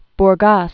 (br-gäs)